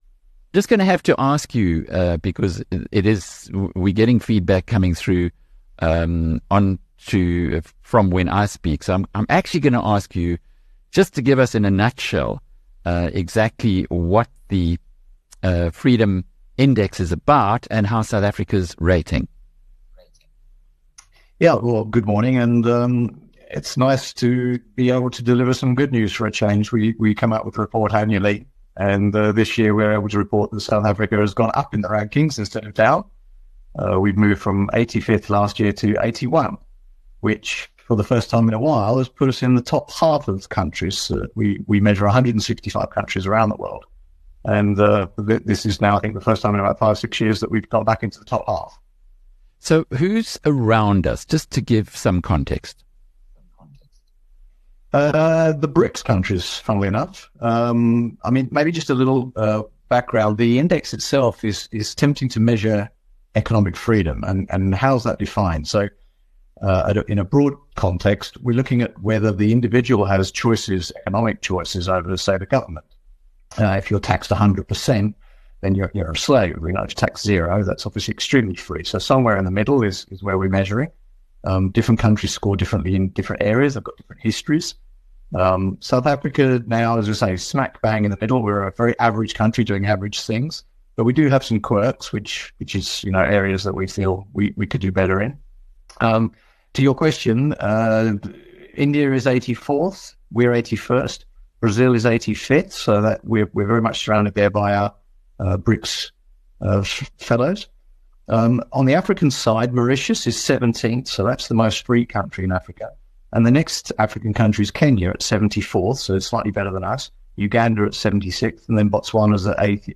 In an insightful interview